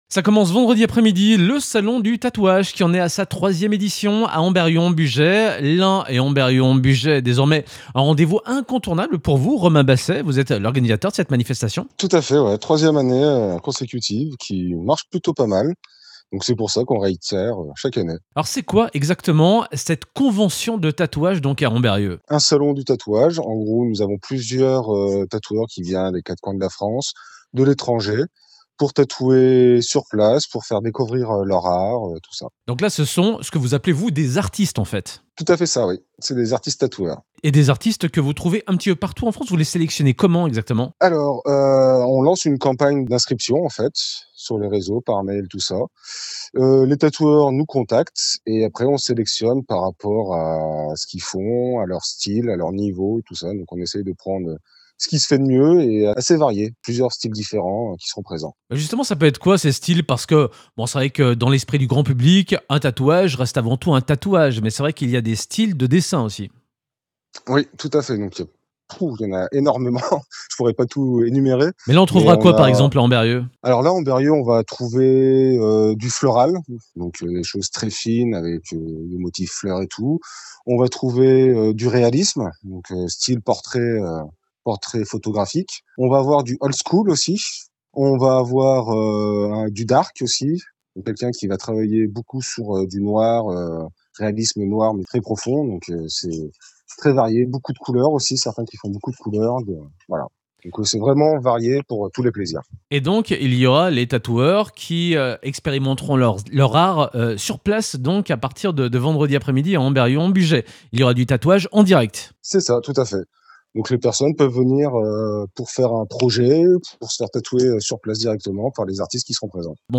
3. Interview de la Rédaction